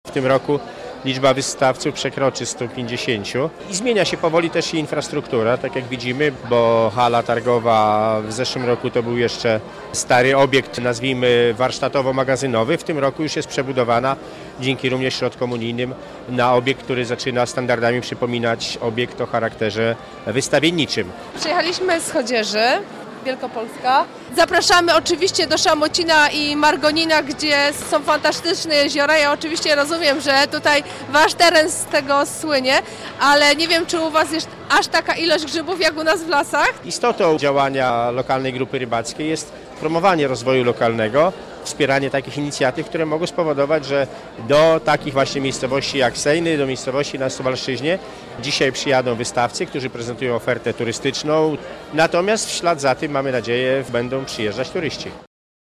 Turystyczne targi w Sejnach - relacja